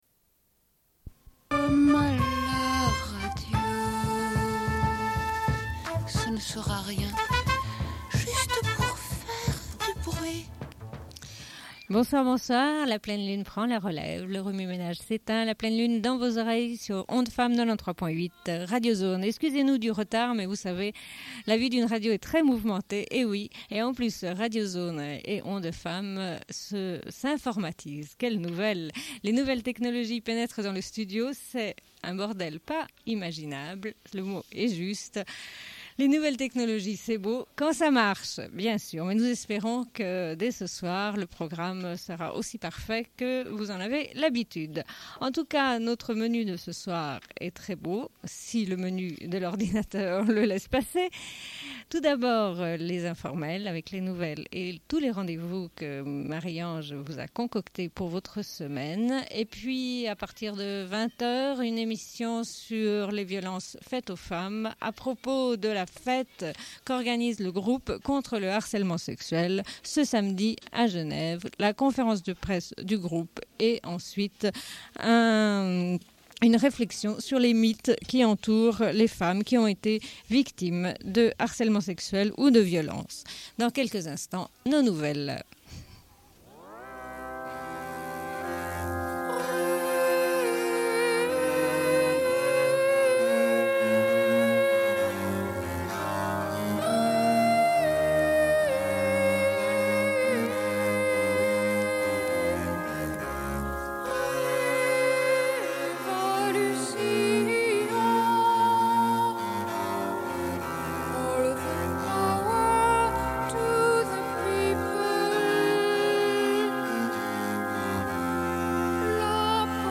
Une cassette audio, face B00:31:23
Bulletin d'information de Radio Pleine Lune. Nouvelles et rendez-vous.